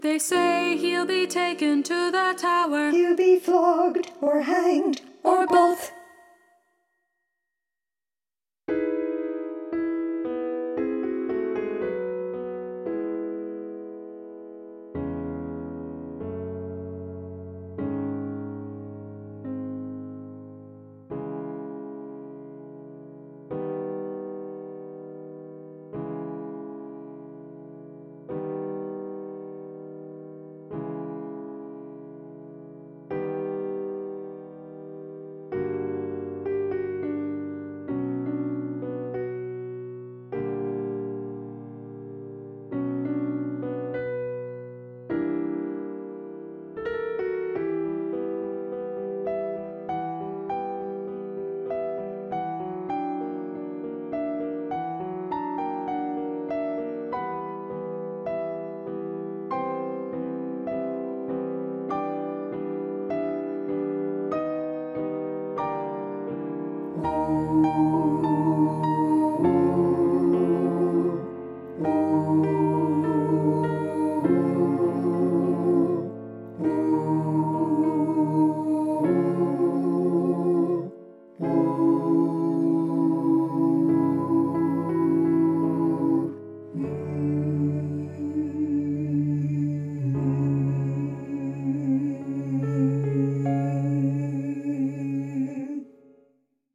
Hear the passage with just piano and chorus (this is what will be played at your audition)